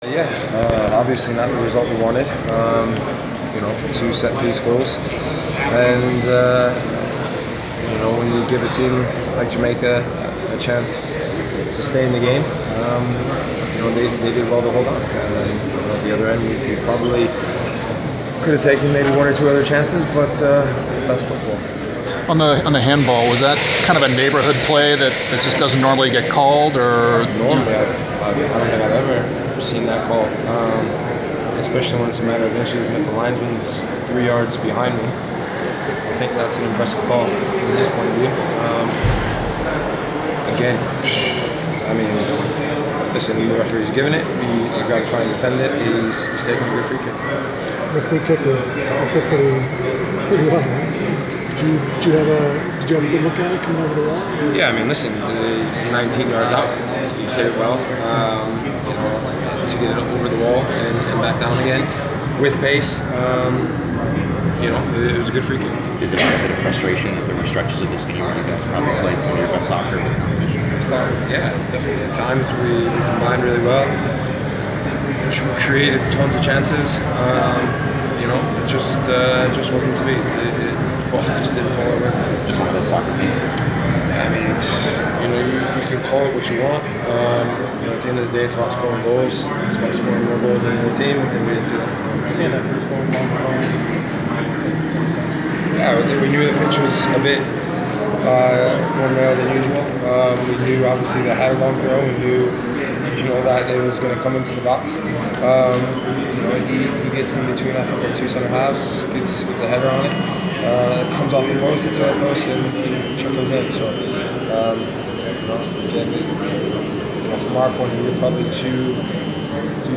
Inside the inquirer: Post-match interview with United States men’s soccer goalkeeper Brad Guzan 7.22.15
The Sports Inquirer caught up with United States men’s soccer goalkeeper Brad Guzan following his team’s 2-1 loss to Jamaica in the semifinals of the Gold Cup at the Georgia Dome in Atlanta on July 22.